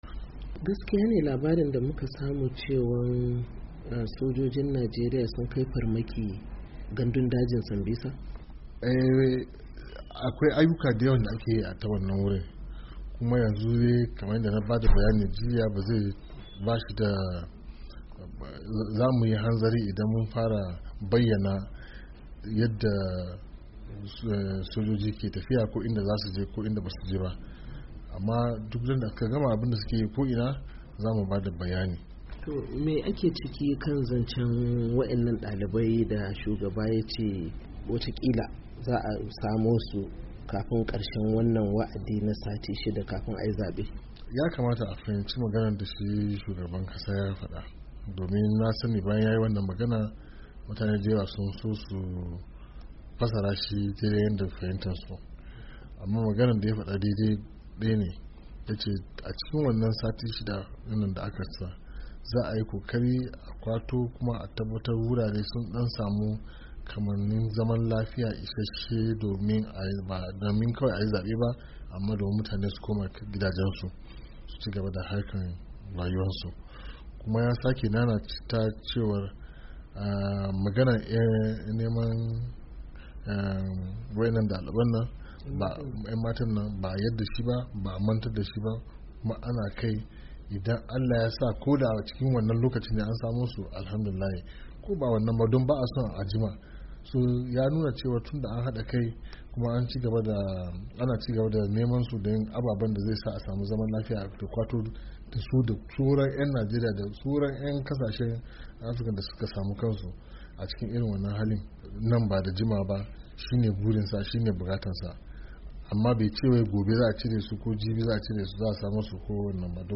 Tattaunawar